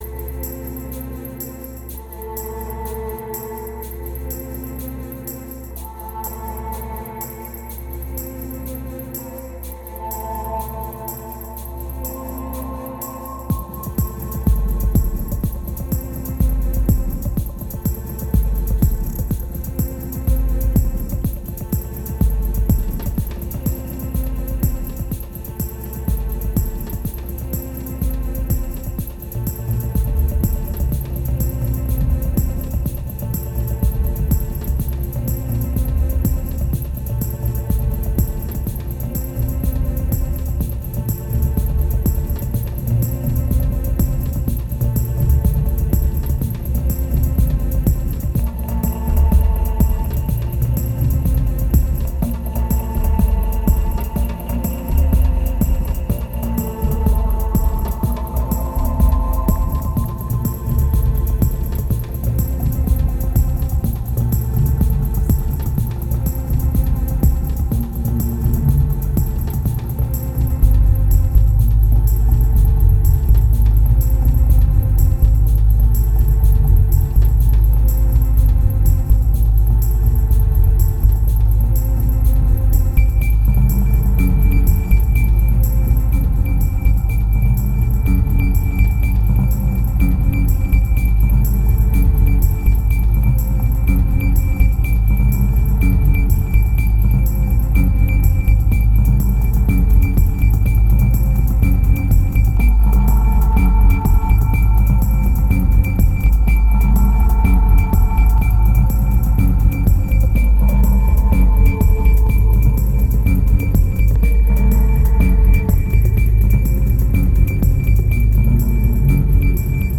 2079📈 - -60%🤔 - 124BPM🔊 - 2010-12-05📅 - -335🌟